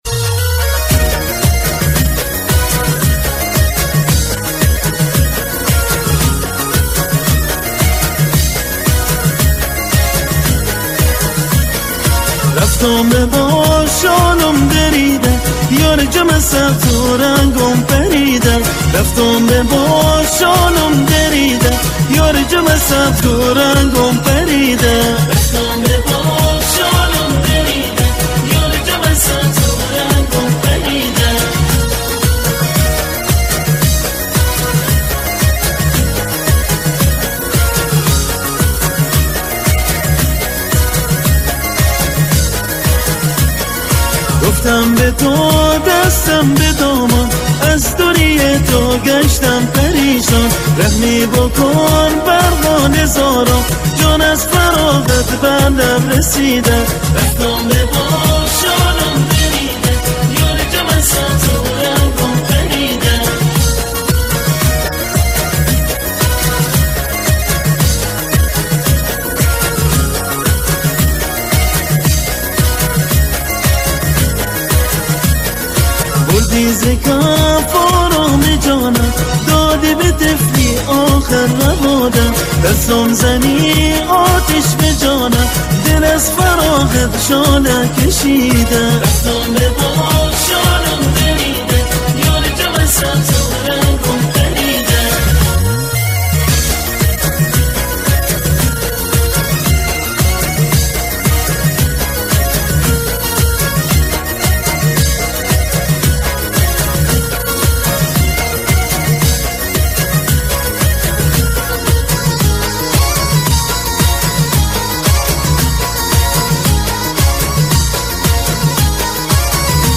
اهنگ بندری
ارکستری